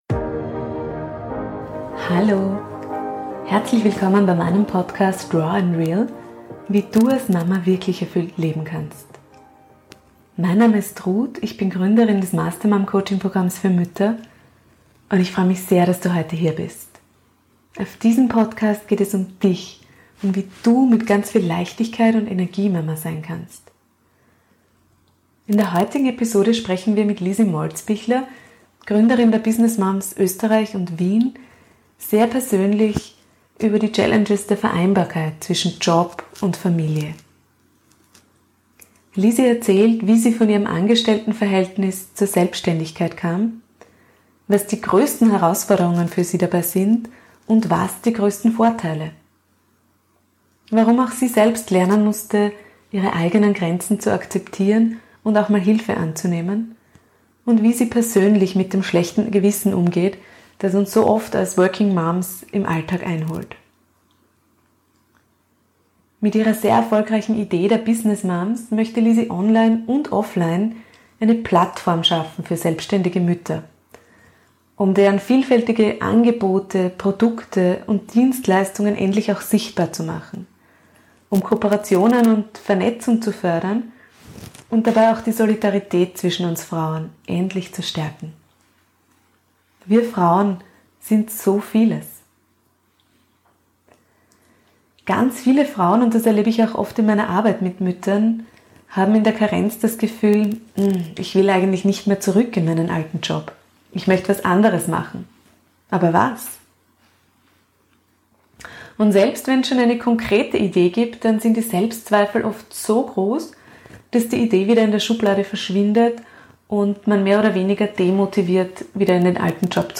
#40 Frauen müssen sich endlich vernetzen. Interview